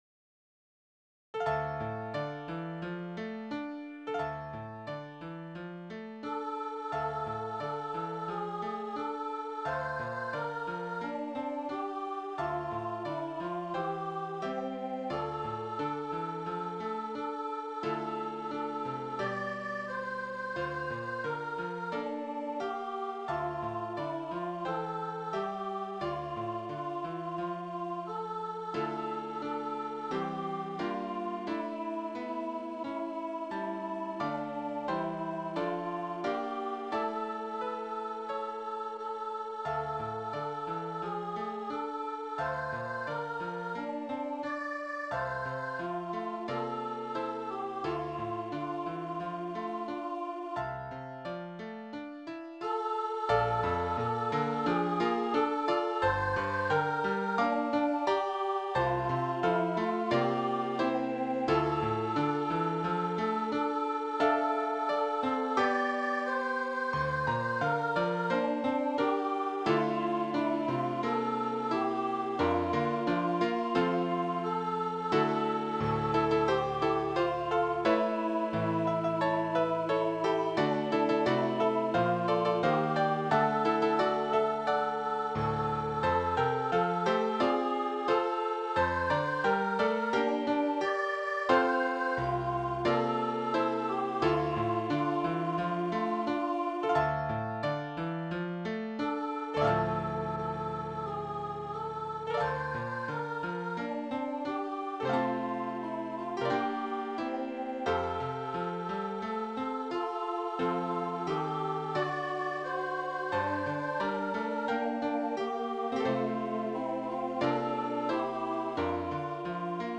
O Little Town Of Bethlehem, Solo with SSA
Voicing/Instrumentation: SSA We also have other 51 arrangements of " O Little Town Of Bethlehem ".